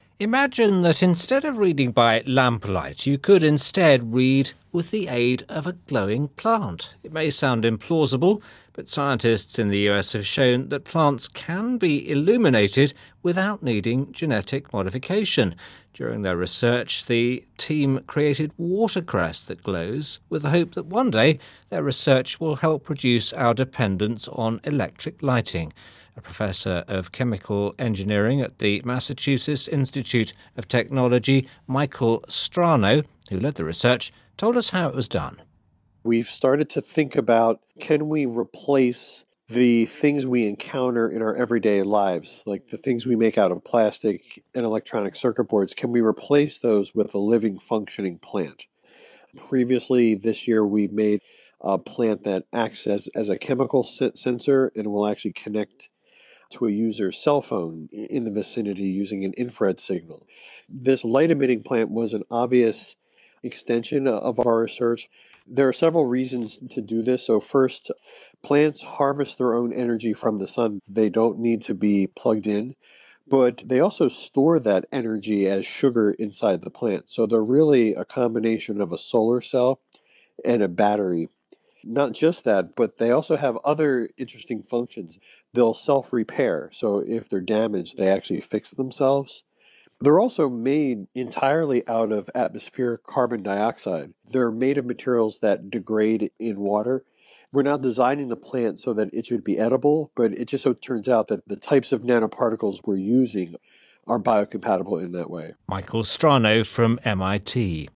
Accent: British, American